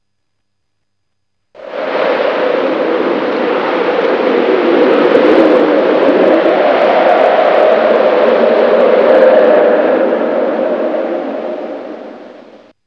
Blizzard
blizzard.wav